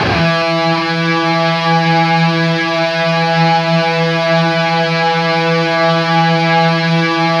LEAD E 2 LP.wav